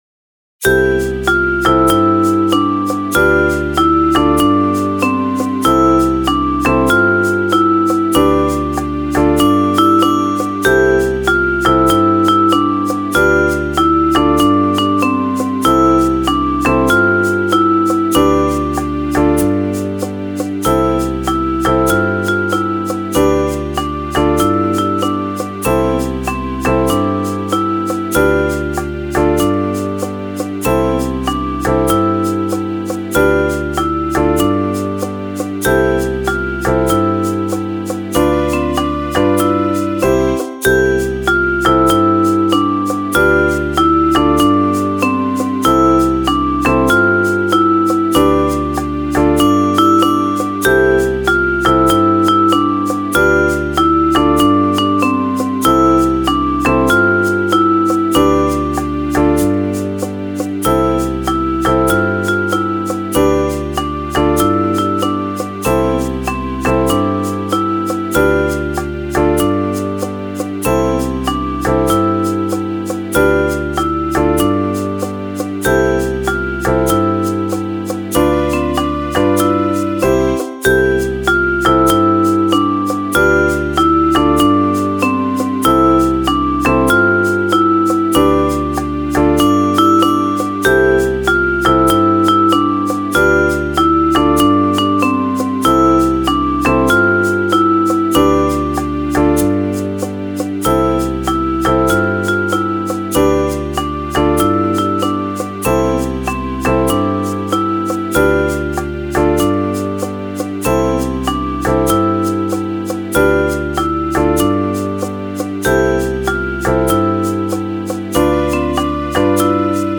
Full Ensemble